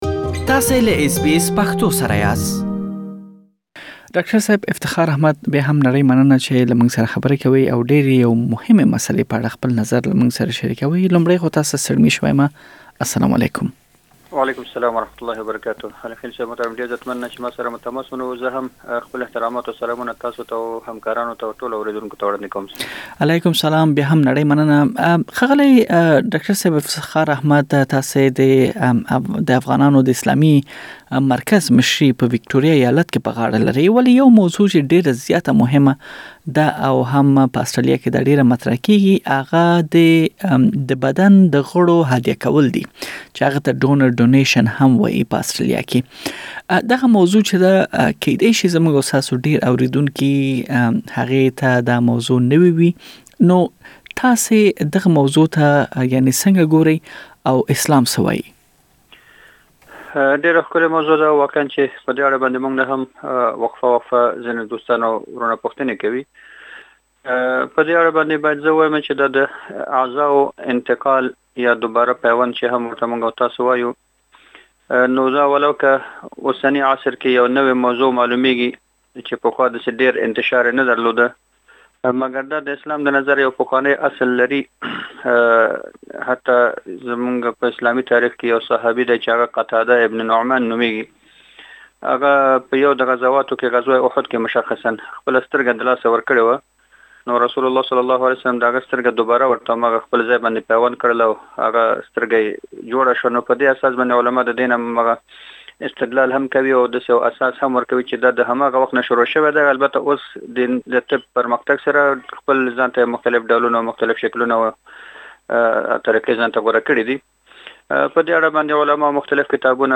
بشپړه مرکه دلته واورئ.